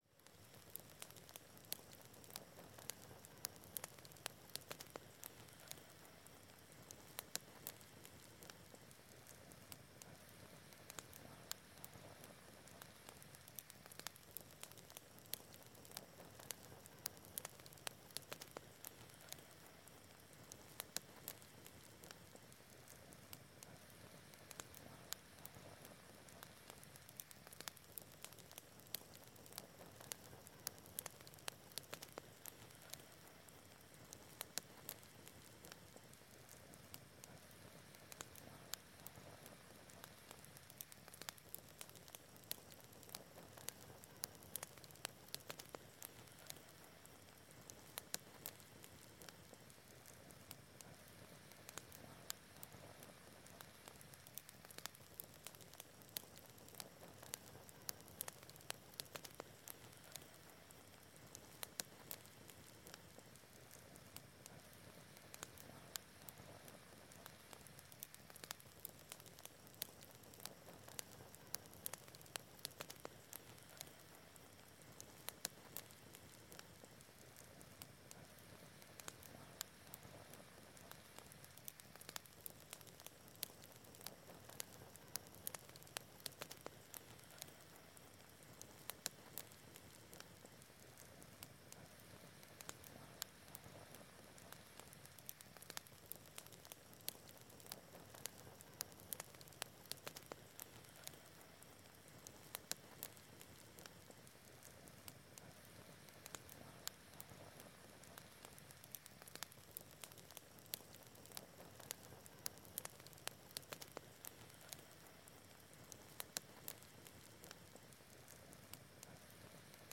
FOGATA PRIMER PLANO BRASA – Los sonidos del Perú
FOGATA-PRIMER-PLANO-BRASA.mp3